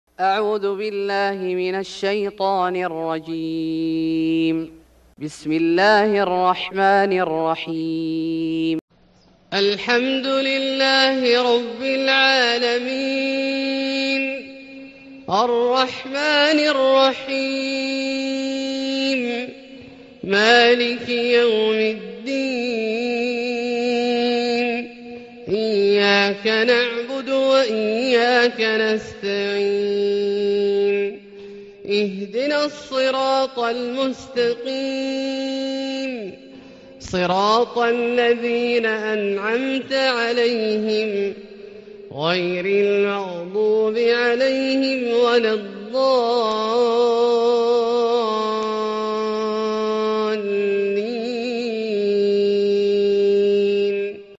سورة الفاتحة Surat Al-Fatihah > مصحف الشيخ عبدالله الجهني من الحرم المكي > المصحف - تلاوات الحرمين